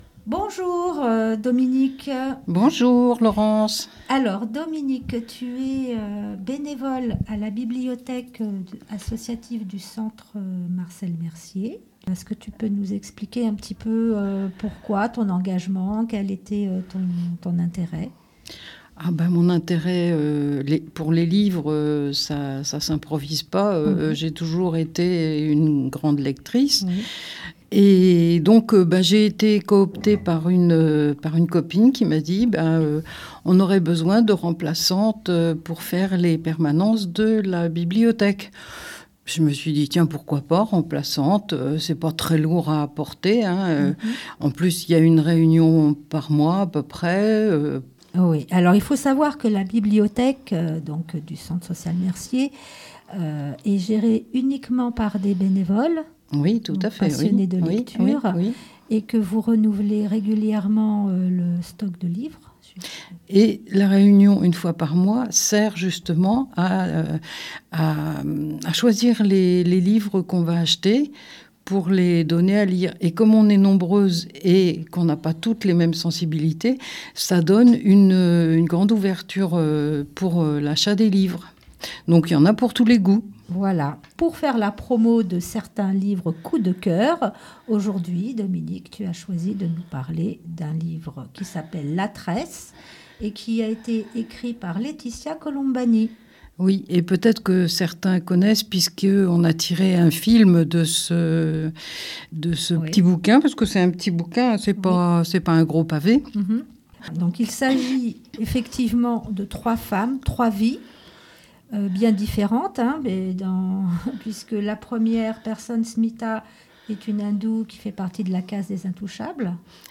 nous racontre brievement l'histoire et nous partage un extrait du livre.